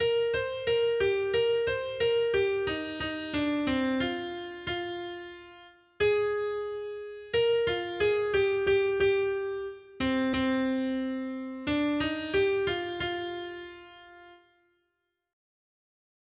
Noty Štítky, zpěvníky ol91.pdf responsoriální žalm Žaltář (Olejník) 91 Ž 25, 1-22 Skrýt akordy R: Rozpomeň se, Hospodine, na své slitování. 1.